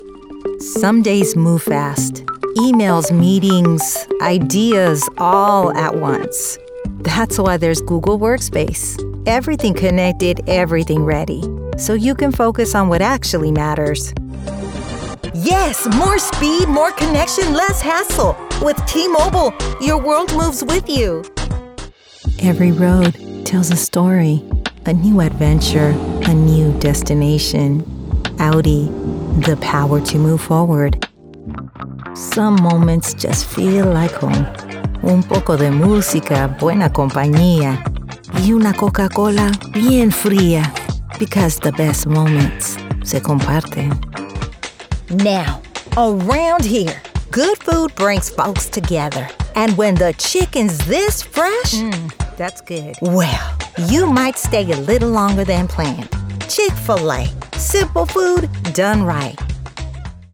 Never any Artificial Voices used, unlike other sites.
Our voice over talent record in their professional studios, so you save money!
Adult (30-50)